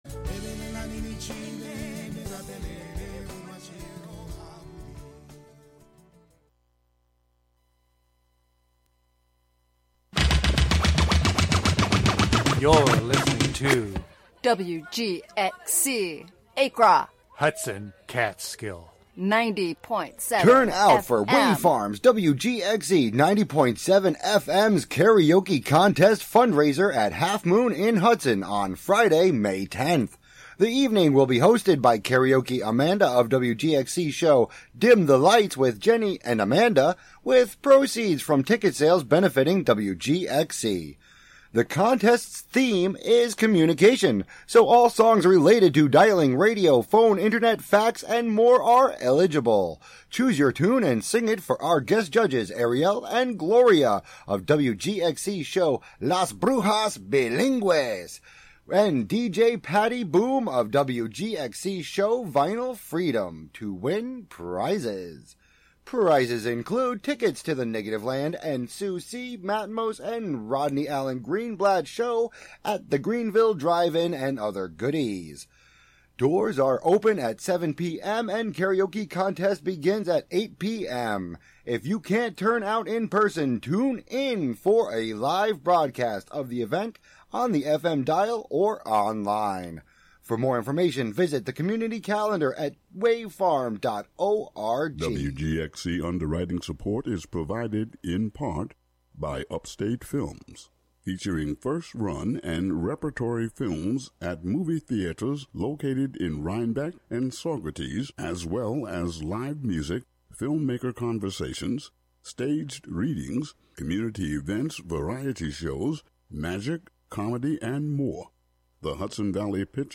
West African Music